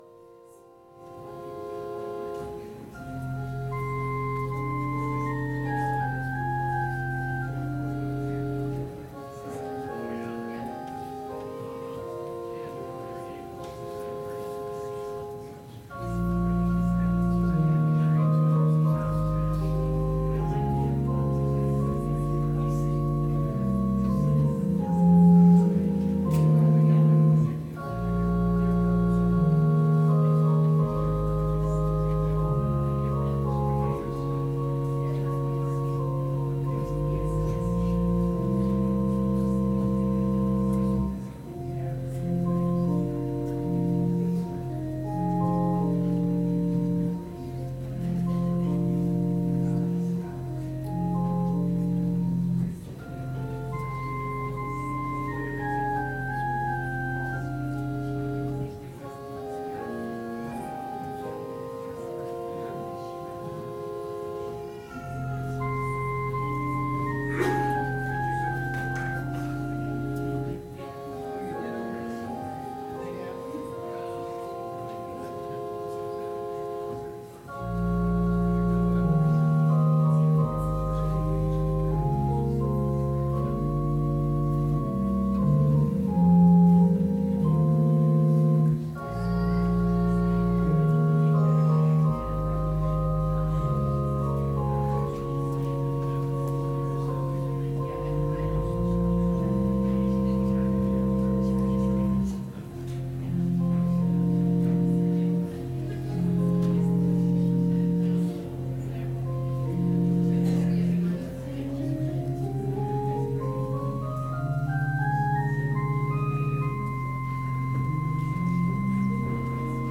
Lent 5 Witnesses to Christ Worship Service